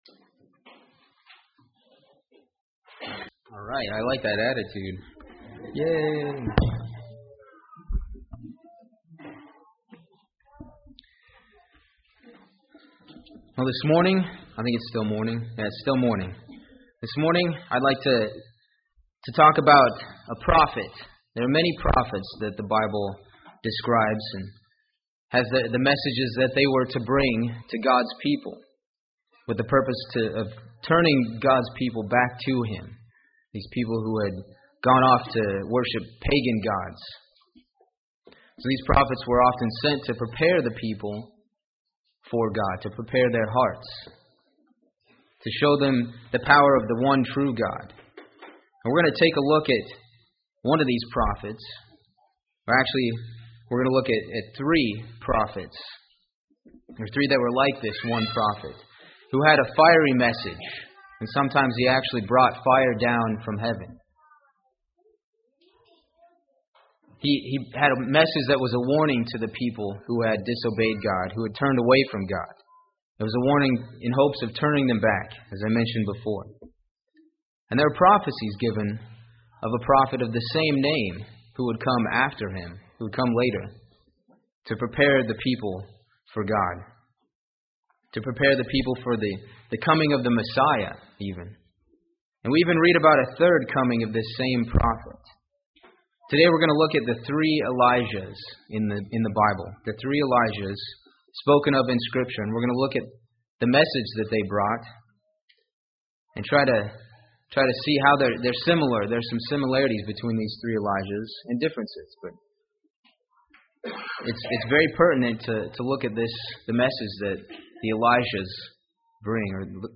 He sent the prophet Elijah to bring a strong message, and there was a prophecy about a second Elijah. There is even a brief mention of a third Elijah to come. This sermon takes a look at these three Elijahs mentioned in scripture.